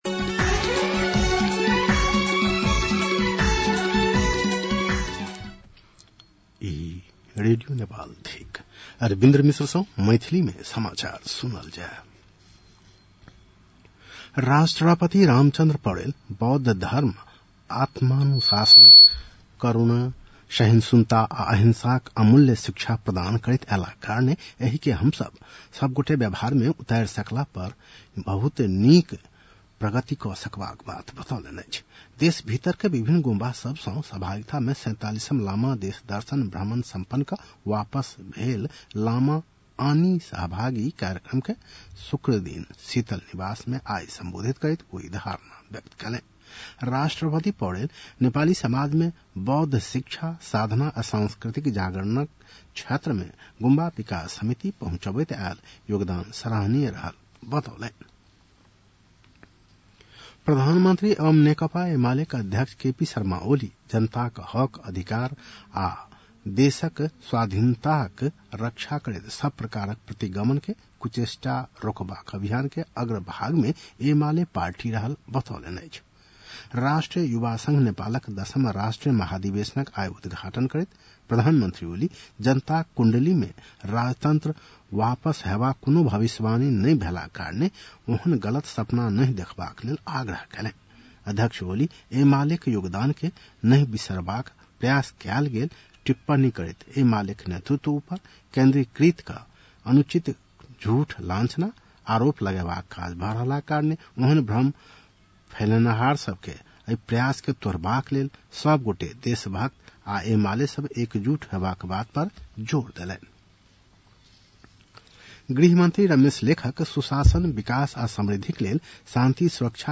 मैथिली भाषामा समाचार : ९ जेठ , २०८२
Maithali-news-2-09.mp3